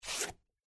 鞋与地面的摩擦2-YS070525.mp3
通用动作/01人物/01移动状态/鞋与地面的摩擦2-YS070525.mp3